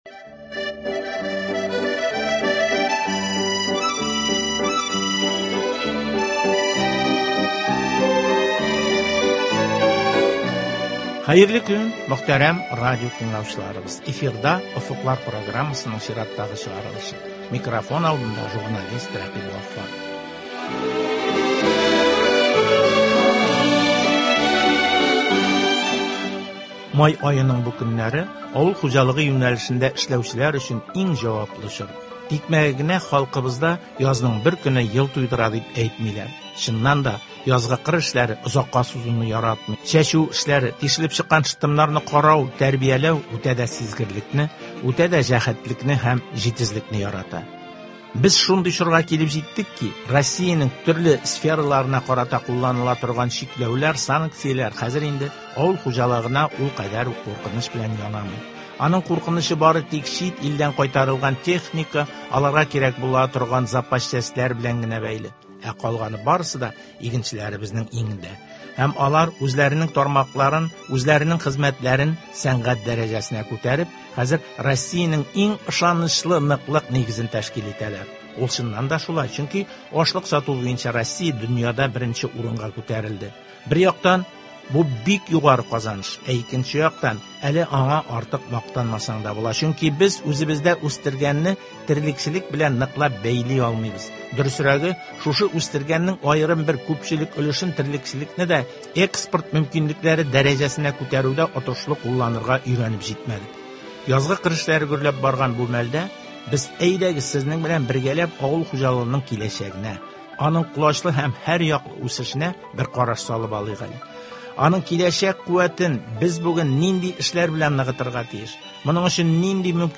җентекле әңгәмә.